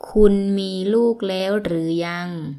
– kunn – mii ∧ luug / laeaeo ∨ rueue – yang